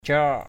/crɔ:ʔ/